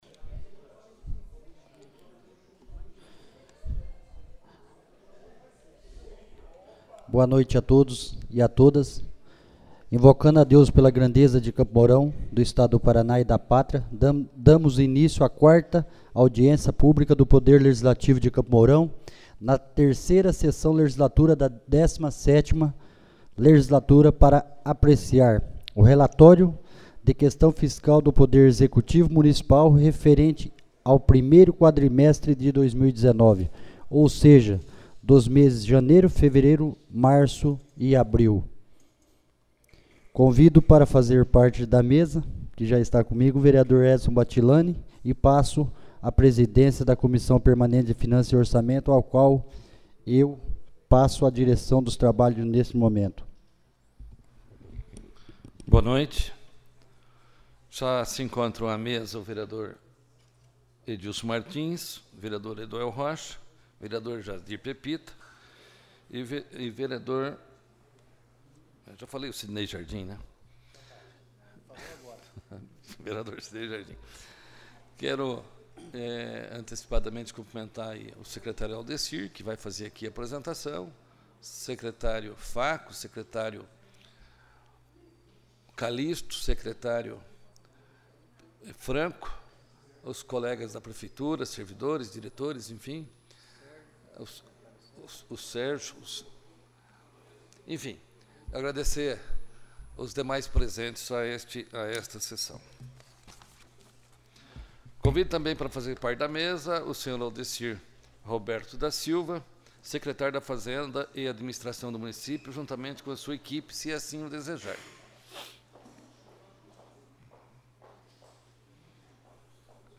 4ª Audiência Pública